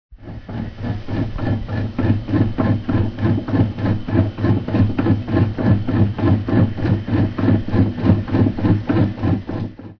thermal-extractor.ogg